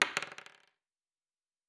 pgs/Assets/Audio/Fantasy Interface Sounds/Dice Single 6.wav at master
Dice Single 6.wav